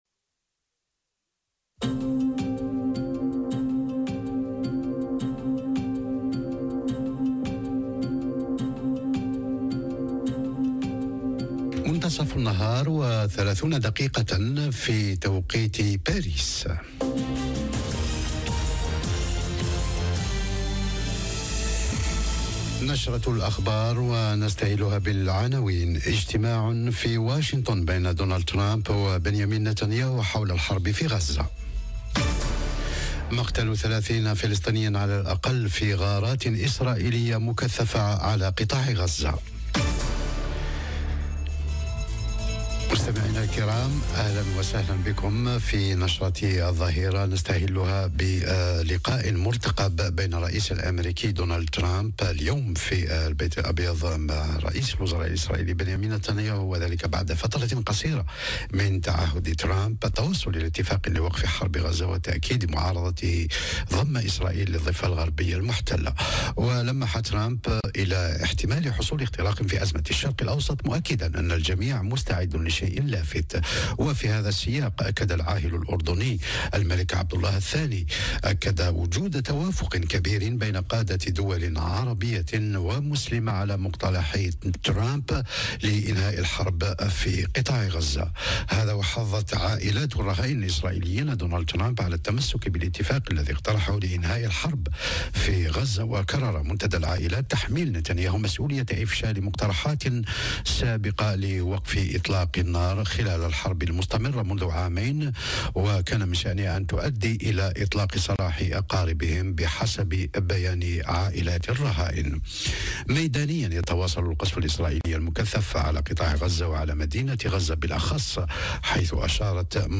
نشرة أخبار الظهيرة: اجتماع هام في البيت الأبيض بين ترامب ونتانياهو حول الحرب في قطاع غزة - Radio ORIENT، إذاعة الشرق من باريس